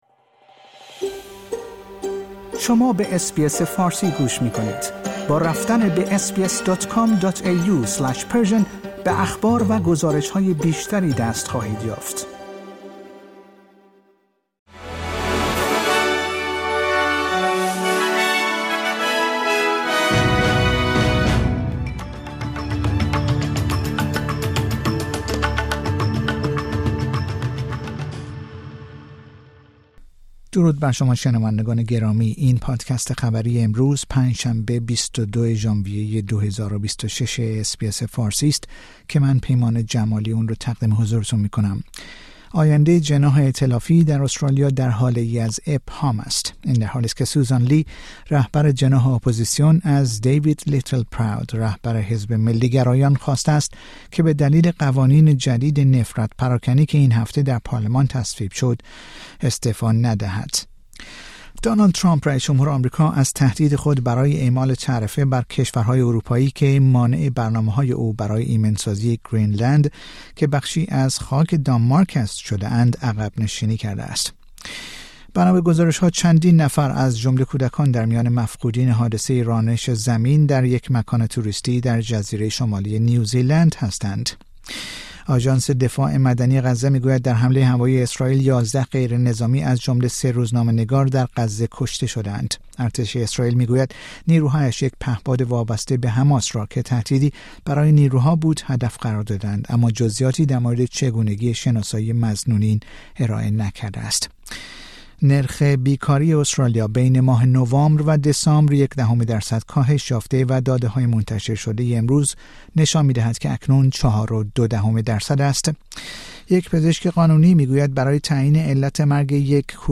در این پادکست خبری مهمترین اخبار روز پنج شنبه ۲۲ ژانویه ۲۰۲۶ ارائه شده است.